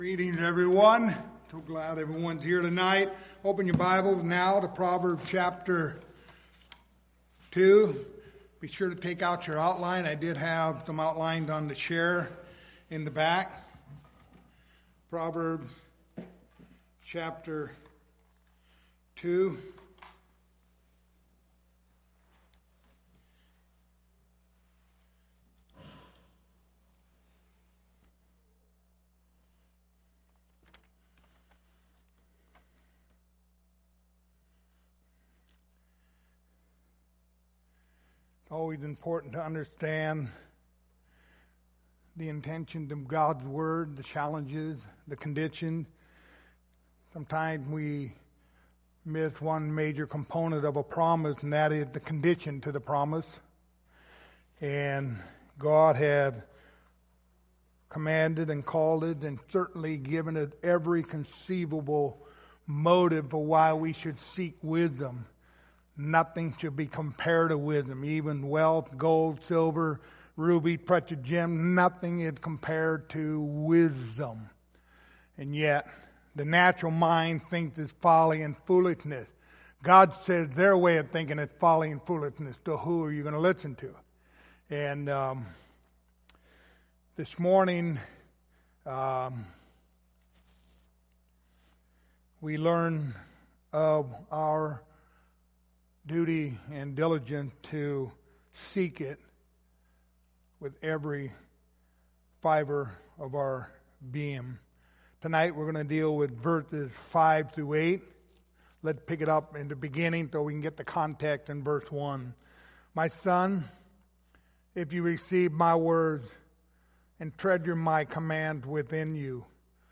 Proverbs 2:5-8 Service Type: Sunday Evening Topics